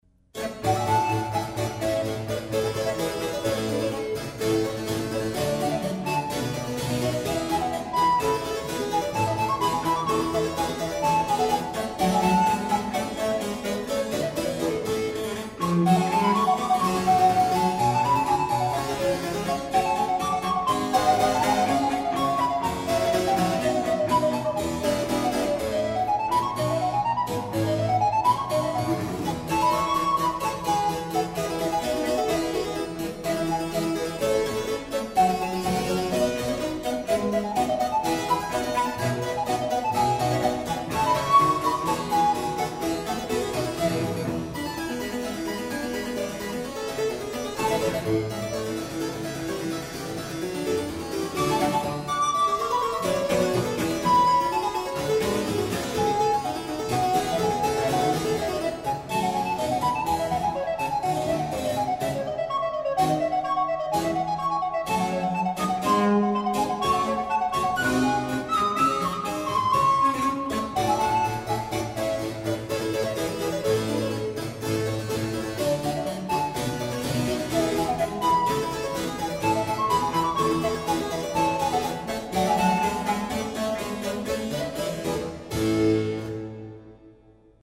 flauto dolce e traverso
clavicembalo e organo da camera
violoncello barroco
Vivace.